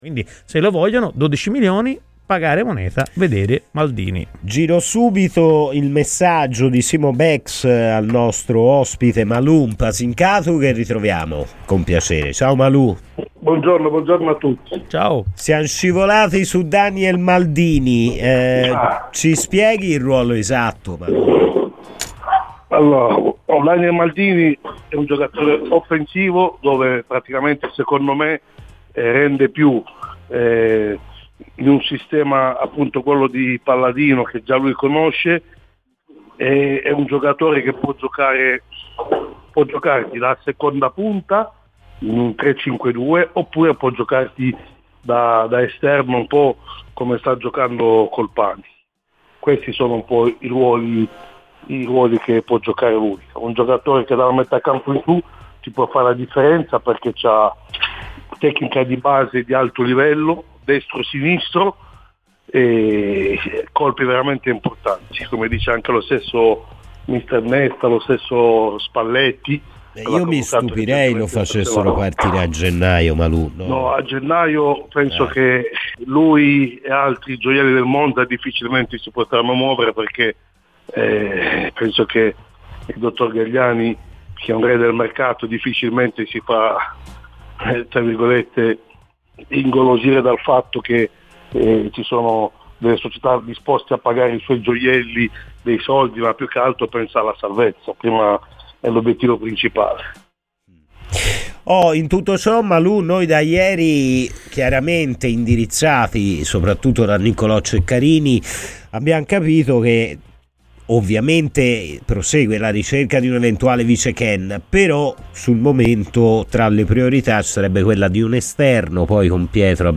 è intervenuto a "Palla al centro" in onda su Radio FirenzeViola.